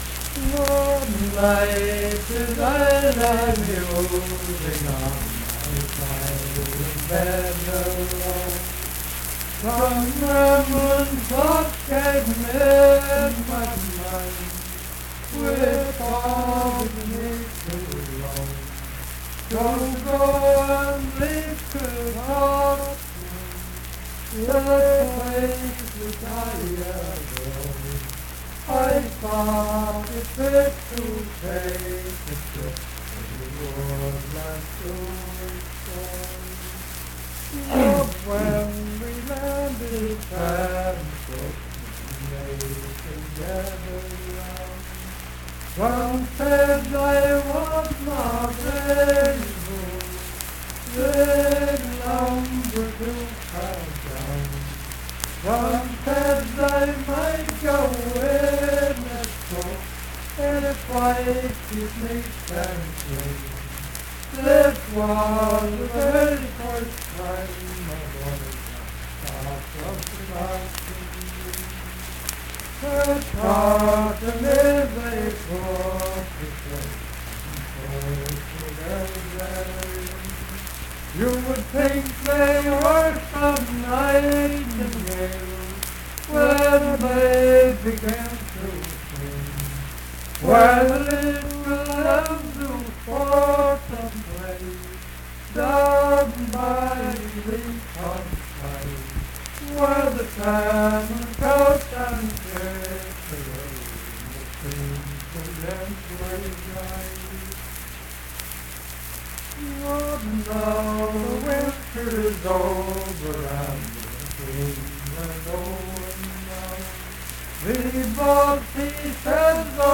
Unaccompanied vocal music
Richwood, Nicholas County, WV.
Verse-refrain 5d(4).
Voice (sung)